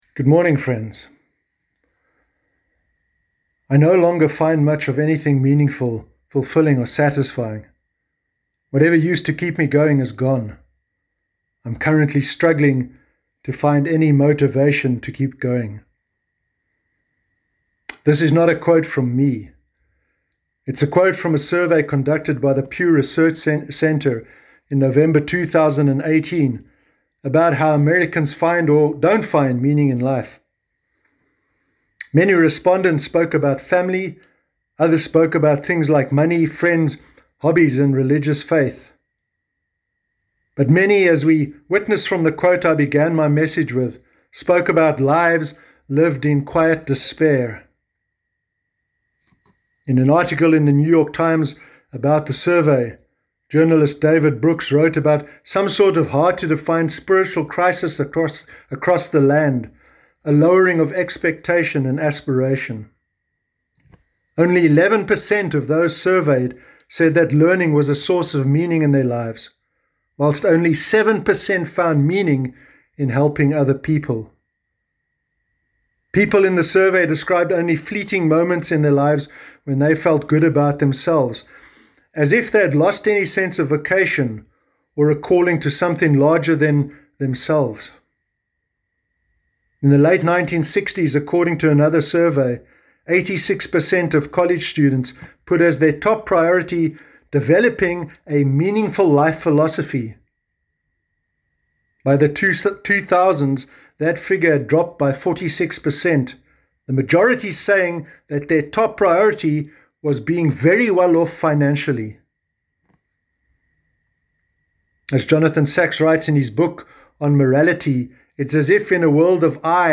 Sermon Sunday 17 May 2020